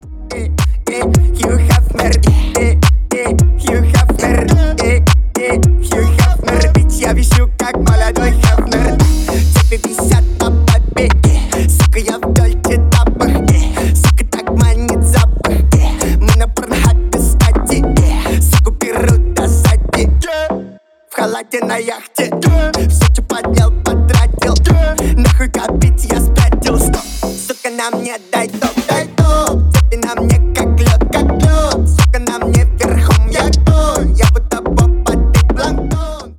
Ремикс
весёлые